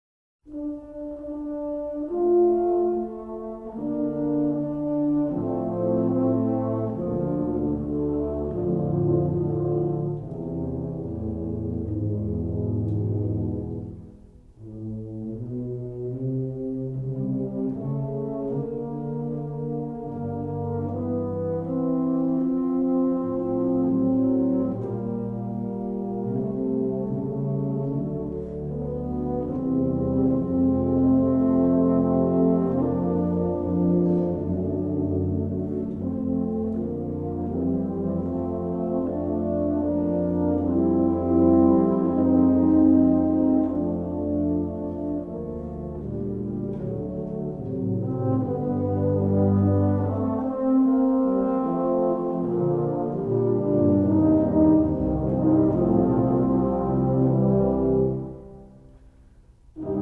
Tuba